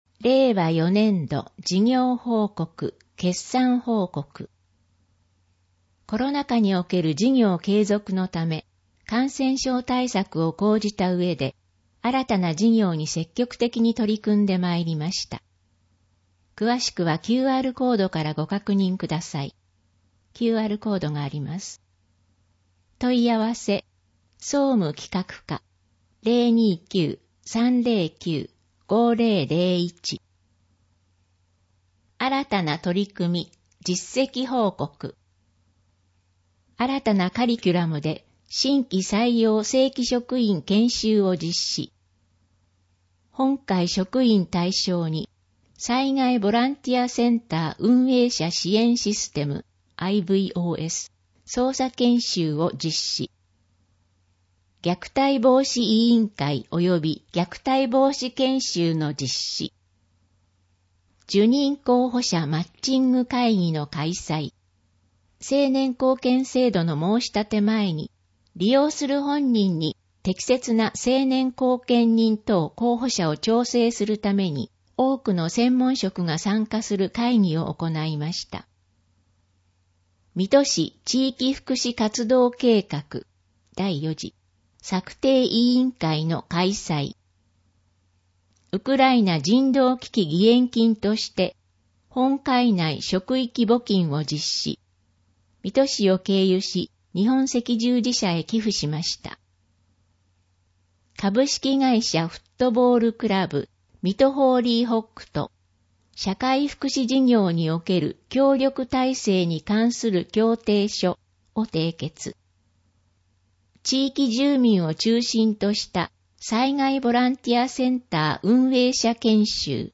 音声ガイド
（音声データ作成：音訳ボランティア「こだま」）